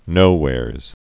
(nōwârz, -hwârz)